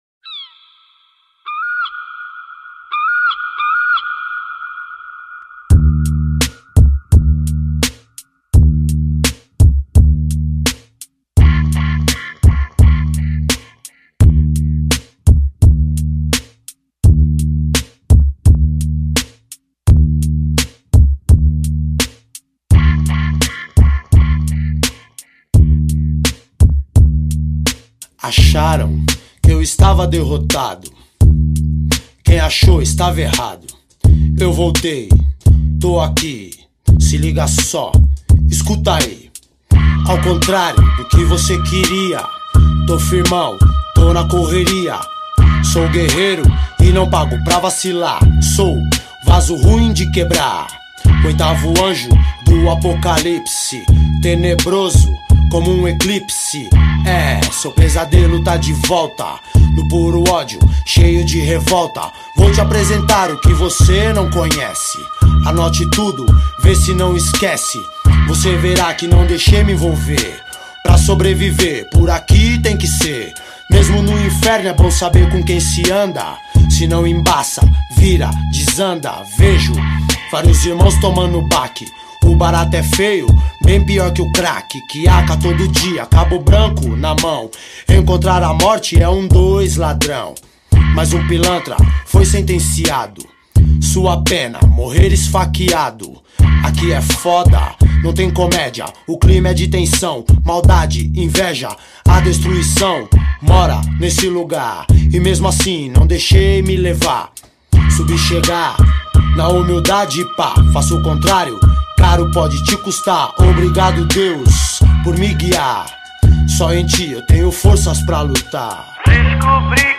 2024-05-10 21:23:39 Gênero: Rap Views